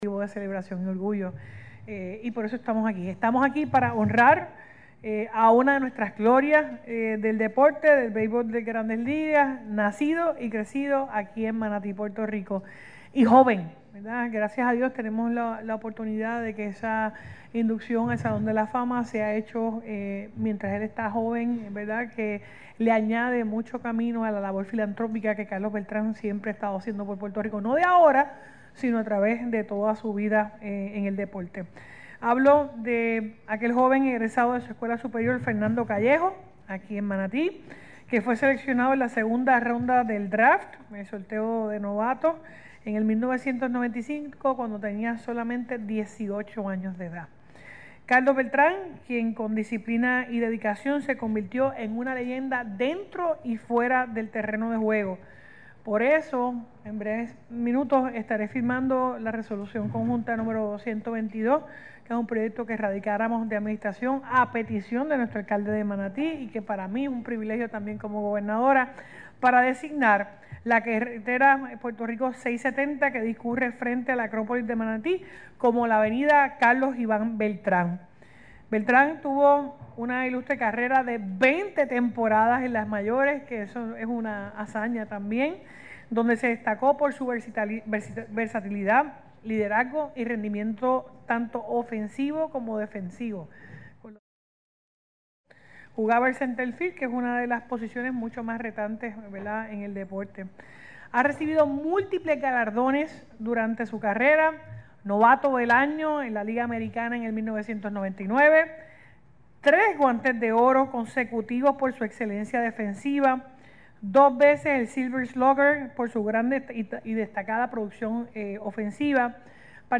“Hoy honramos a una de nuestras grandes glorias del béisbol de Grandes Ligas, un hombre nacido y criado aquí en Manatí, que con esfuerzo, disciplina y dedicación se convirtió en un ejemplo para nuestra juventud. Carlos Beltrán representa lo mejor de Puerto Rico y es motivo de orgullo para todos”, expresó la gobernadora durante la ceremonia.
GOBERNADORA-DEVELACION.mp3